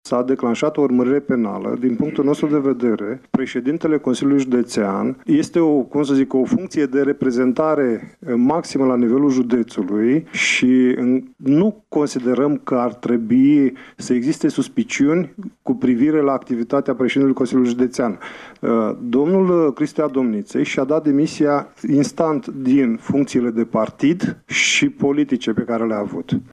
Consilierii judeţeni liberali de Iaşi au solicitat astăzi în şedinţă extraordinară demisia lui Maricel Popa din funcţia de preşedinte al Consiliului Judeţean Iaşi.